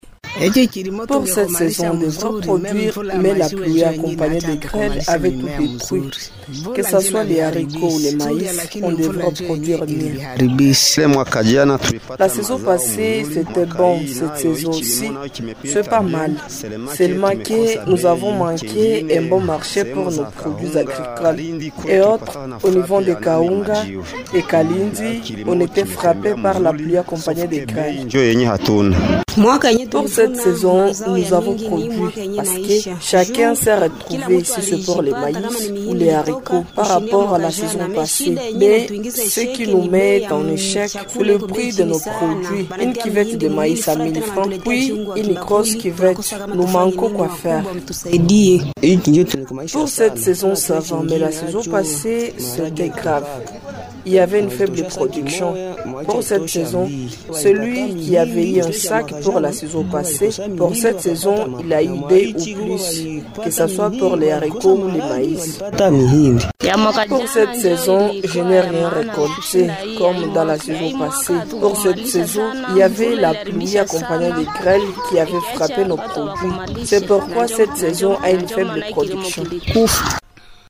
FR-VOX-POP-AGRICULTEURS-KIWANJA-SUR-SAISON.mp3